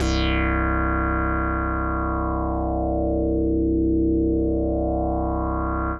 C2_raspy_synth.wav